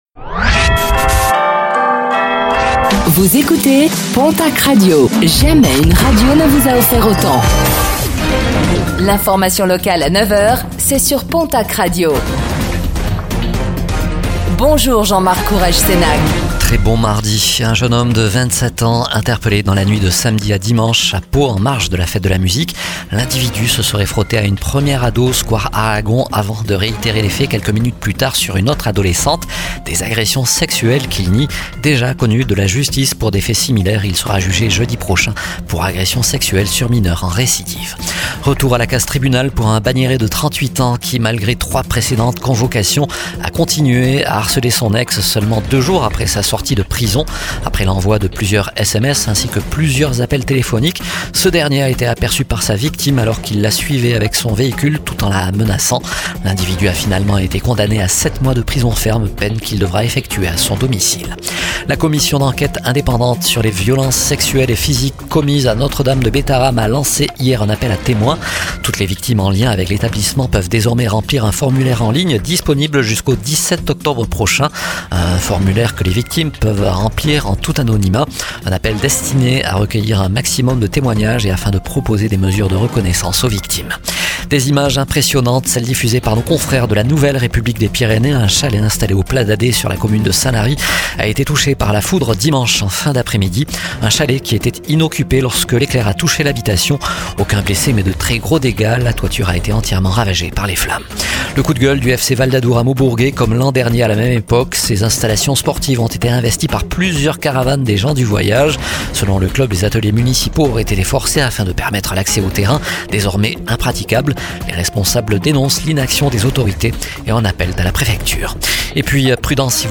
09:05 Écouter le podcast Télécharger le podcast Réécoutez le flash d'information locale de ce mardi 24 juin 2025